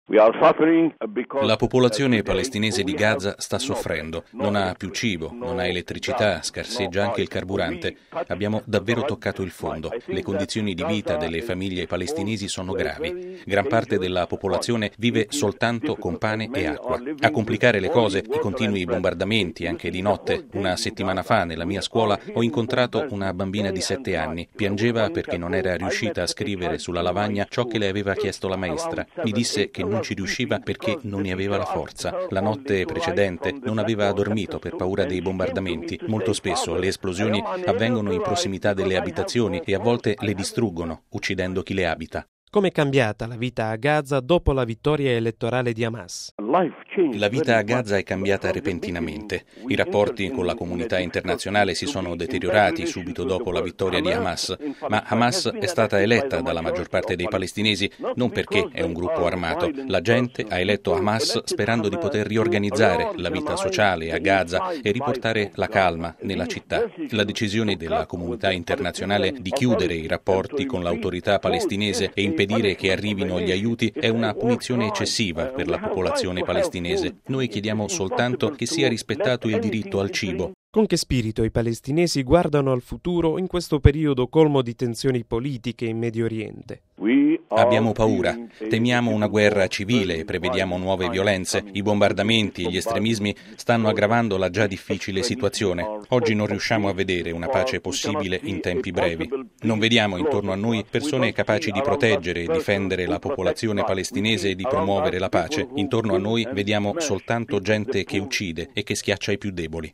raggiunto telefonicamente